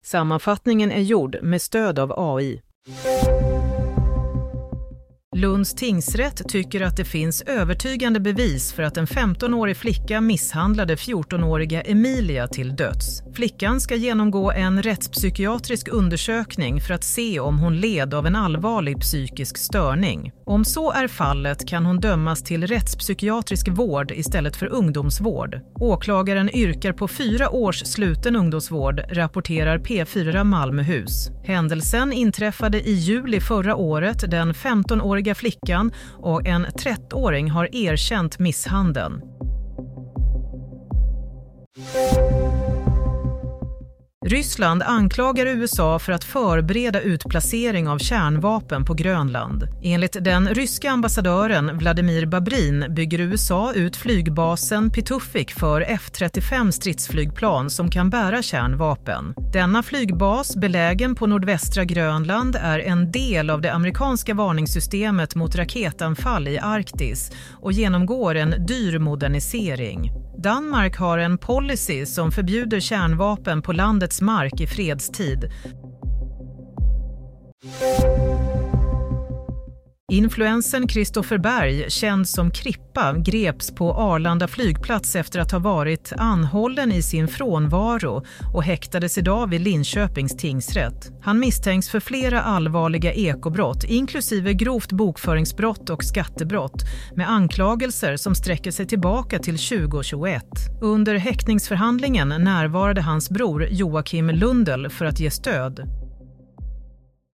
Nyhetssammanfattning – 10 januari 16:00